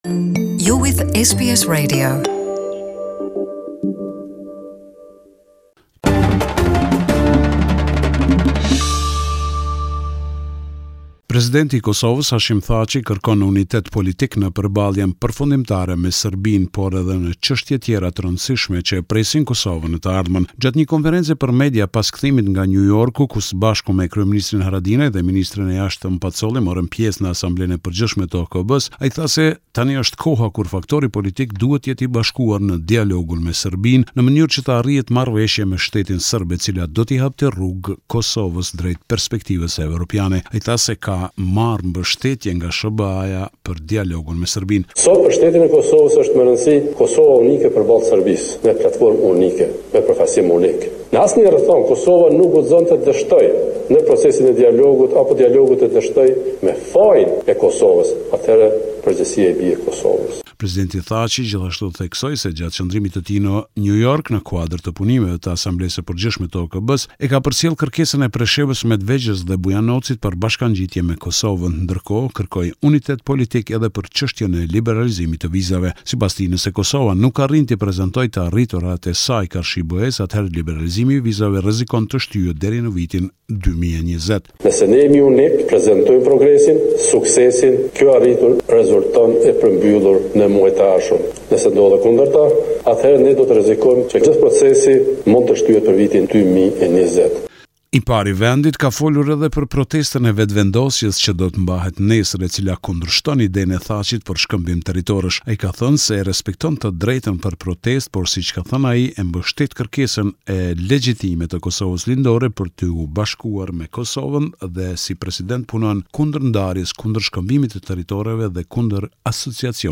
This is a report summarising the latest developments in news and current affairs in Kosovo